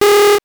powerup_7.wav